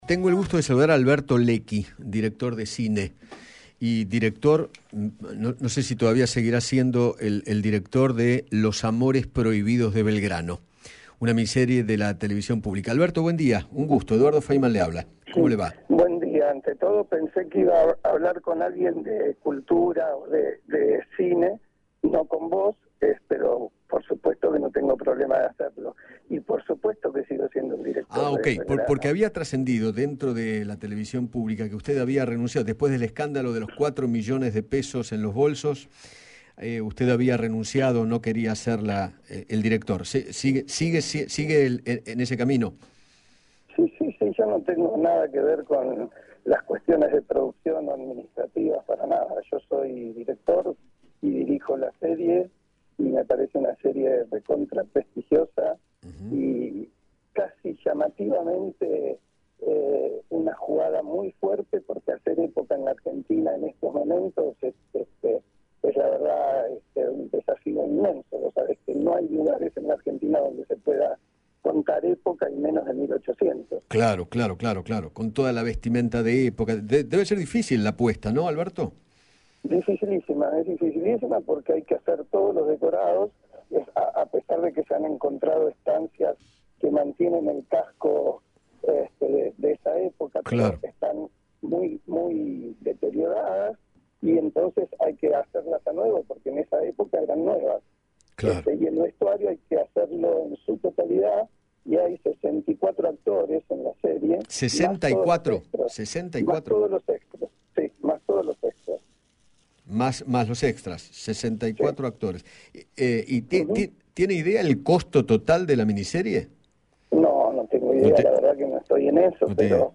Alberto Lecchi, director y guionista argentino, dialogó con Eduardo Feinmann sobre el escándalo del bolso con 4 millones de pesos que apareció en la TV Pública y se refirió a la mini serie de Manuel Belgrano que dirigirá.